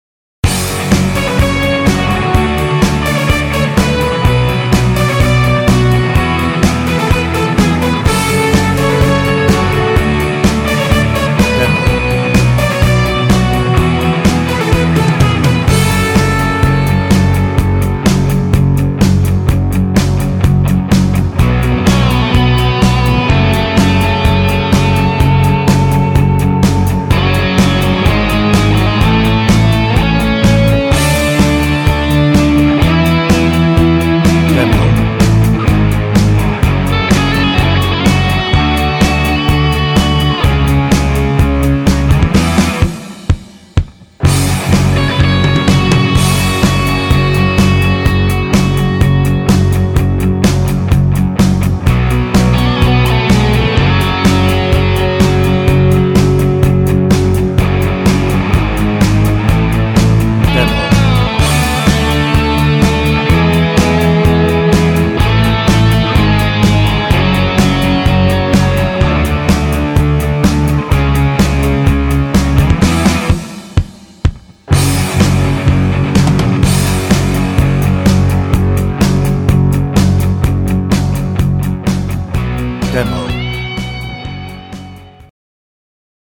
Description Reviews (0) Hoedown - No ref.
Instrumental